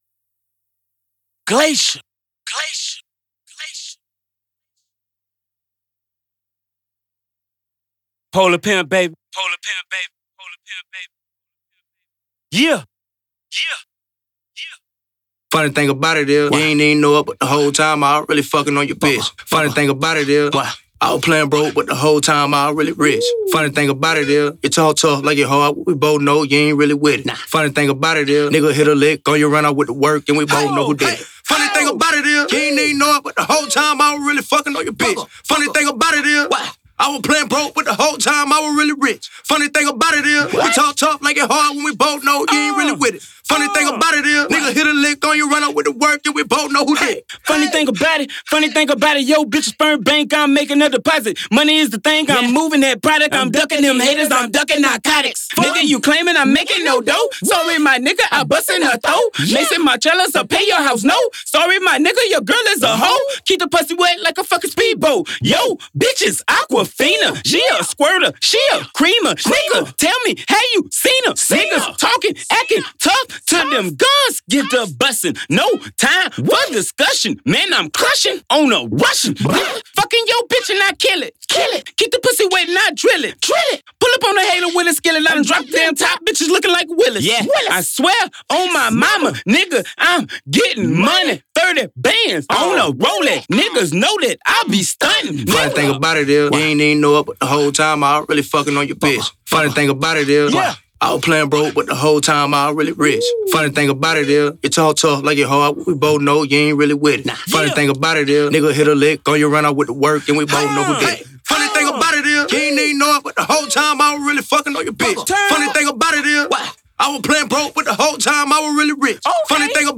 THIS RECORD IS STRONG, POWERFUL, AND A DEFINITE BANGER.